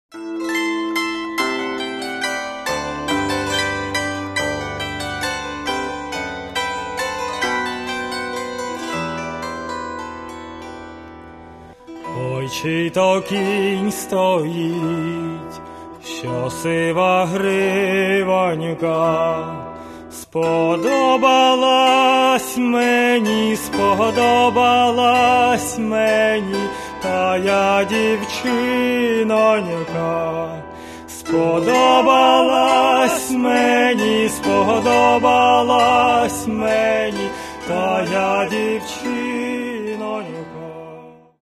Каталог -> Народна -> Сучасні обробки
Буквально кожна пісня дійсно зазвучала і прозвучала.